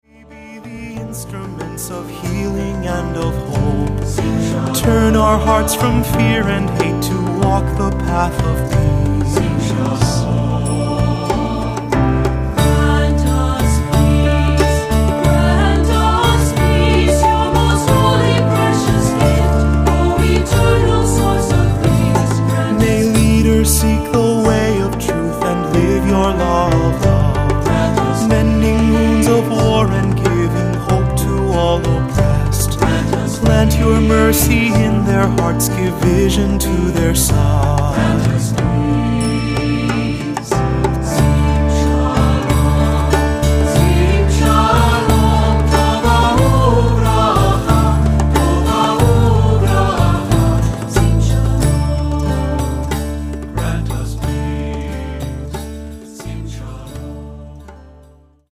Accompaniment:      Keyboard
Music Category:      Christian
For cantor or soloist